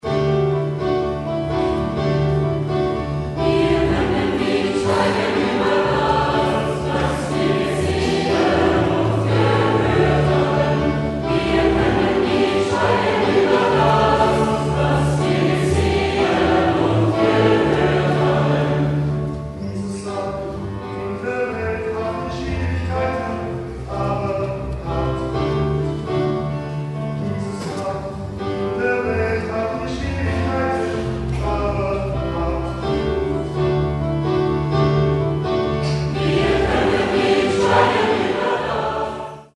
Demo-Aufnahmen - größtenteils Live-Mitschnitte.